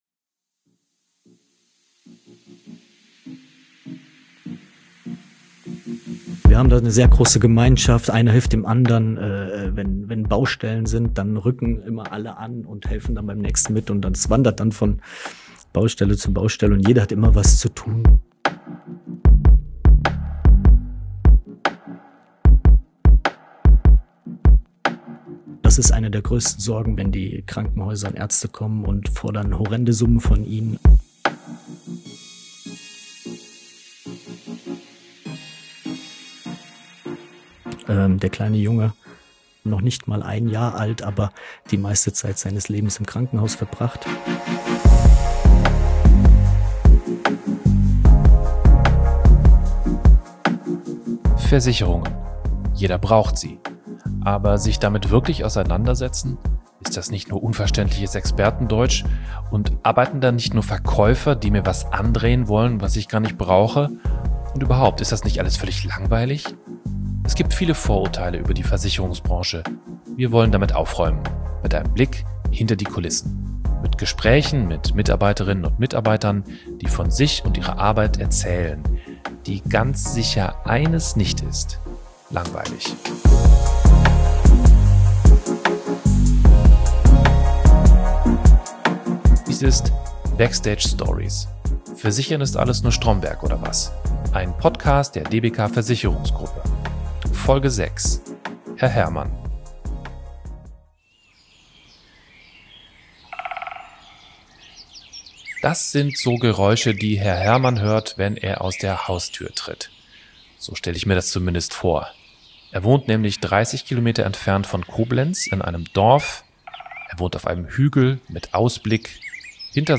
Hier kommen die Menschen zu Wort, die hinter den Kulissen einer Versicherung arbeiten und Geschichten aus ihrem Leben erzählen.
Unser Podcast ist authentisch und nicht geskriptet. Wir zeigen, wer die Debeka ist und für was sie steht.